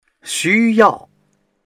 xu1yao4.mp3